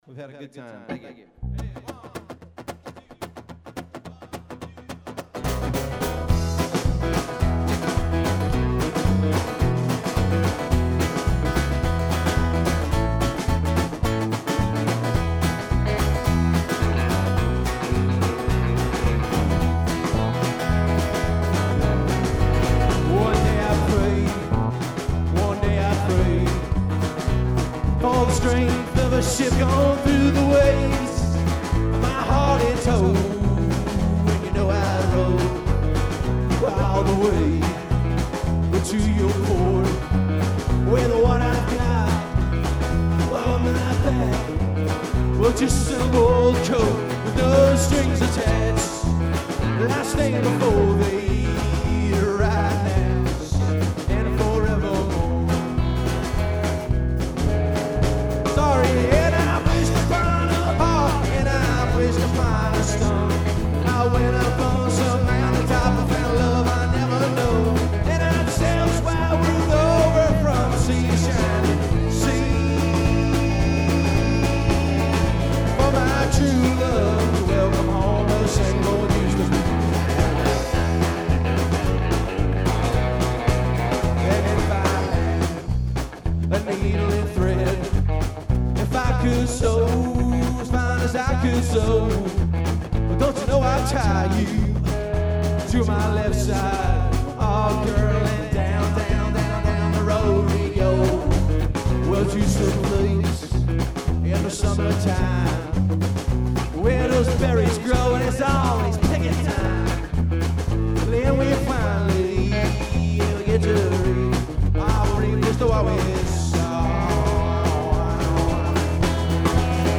Live
Lead Guitar
Bass Guitar, Vocals
Drums